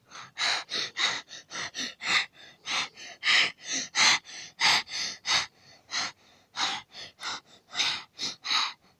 breathing-sound.mp3